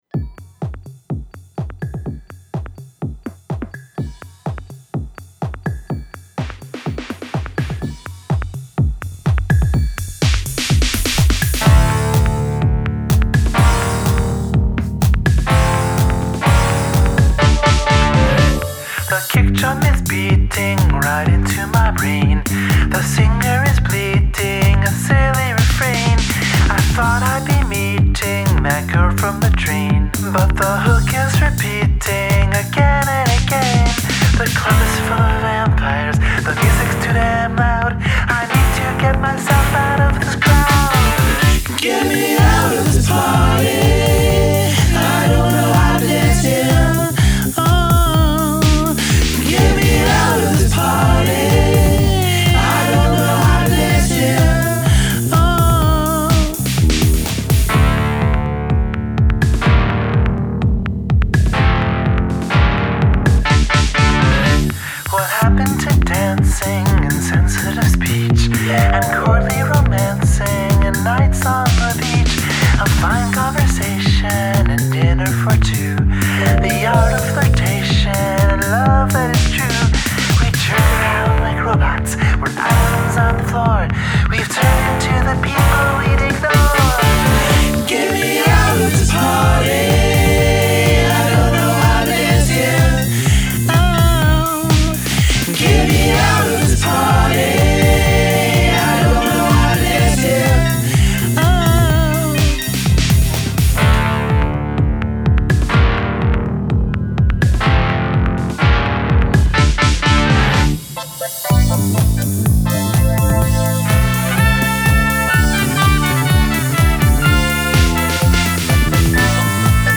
gorgeously cheeky